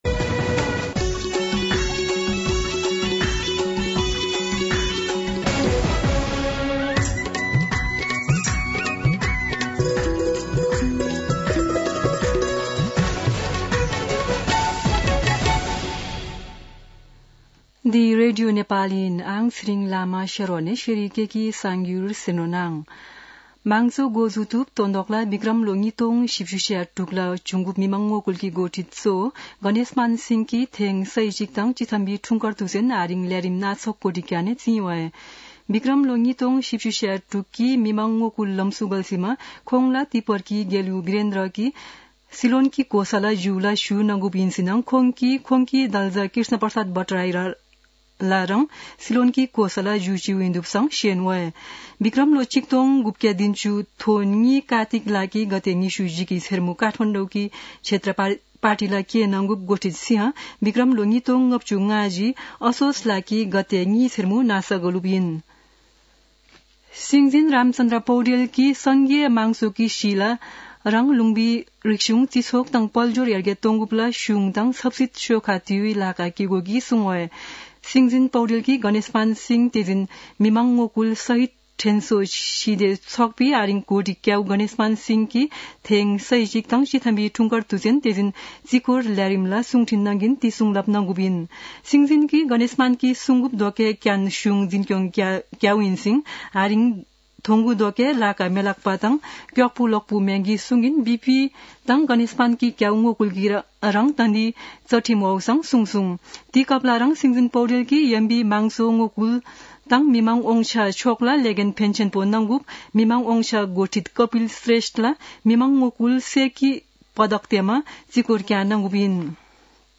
शेर्पा भाषाको समाचार : २५ कार्तिक , २०८१